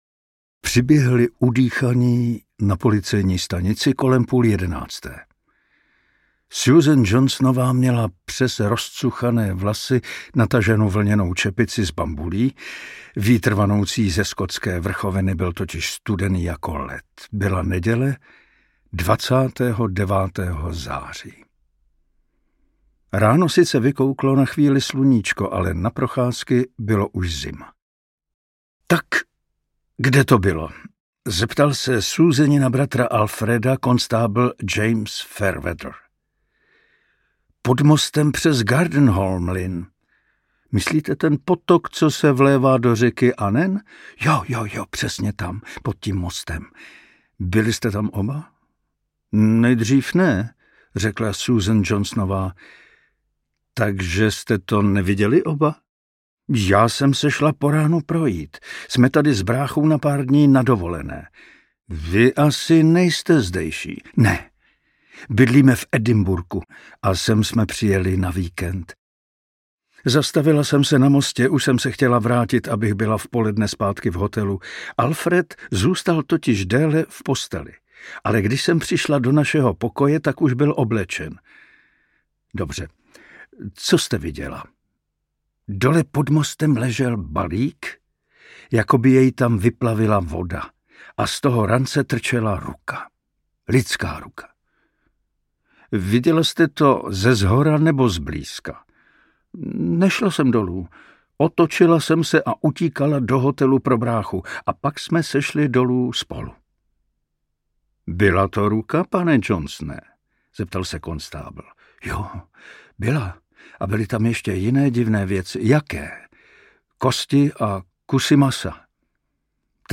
Smrt má v ruce skalpel audiokniha
Ukázka z knihy
Vyrobilo studio Soundguru.